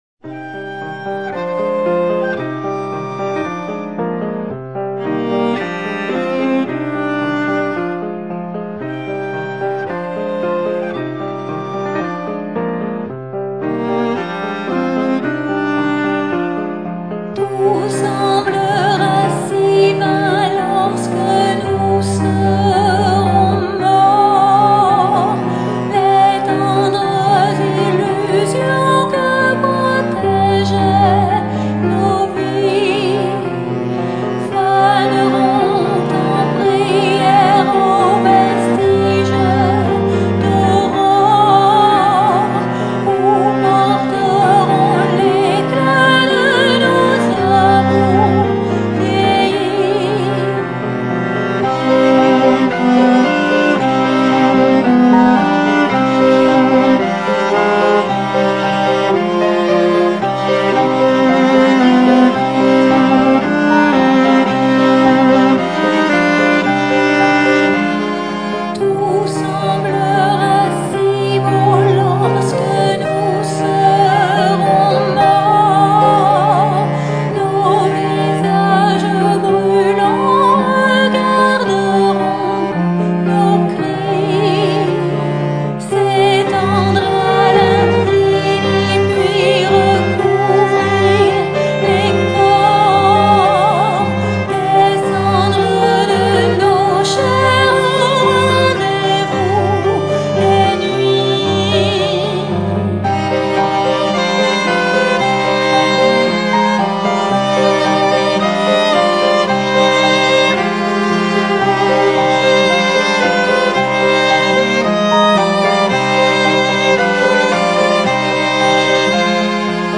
憂愁と耽美が交錯する端正な室内楽
piano, voices
viola
cello